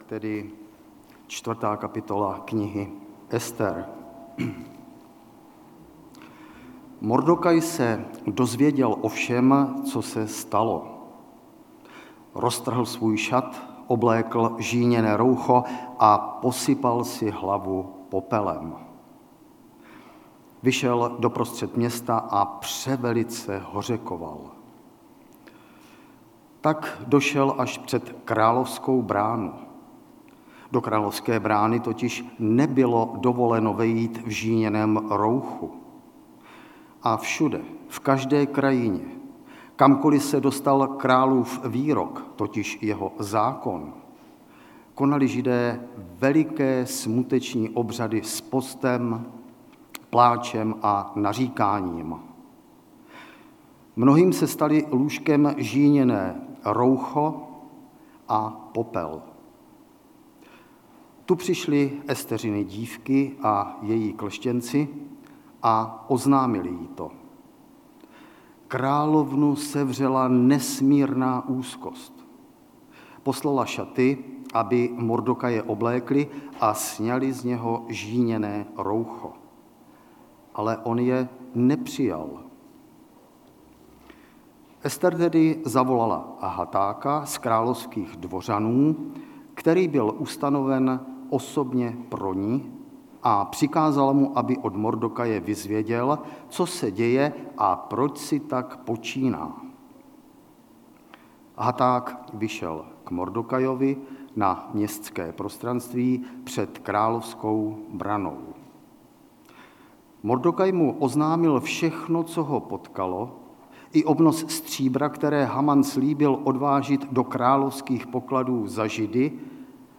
4. díl ze série kázání Ester (4. + 5. kap.)
Kategorie: Nedělní bohoslužby